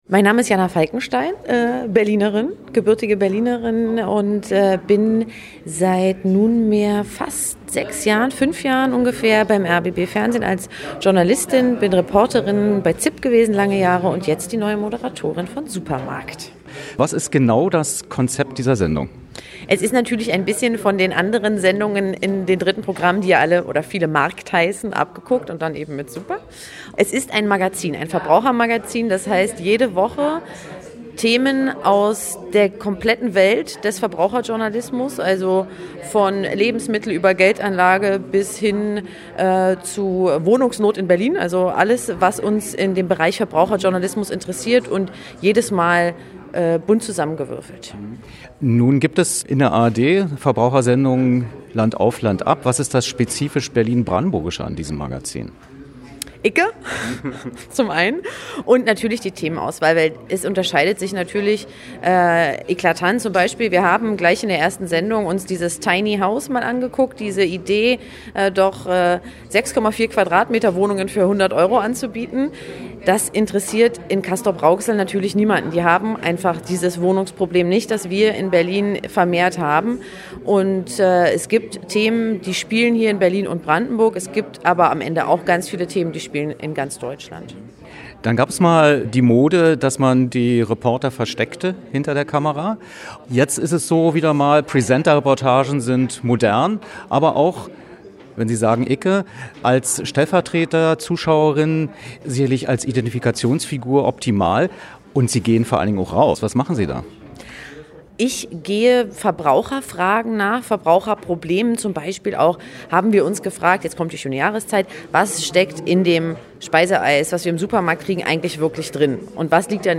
Was: Interview zur Premiere von „Super.Markt“
Wo: Berlin, Haus des Rundfunks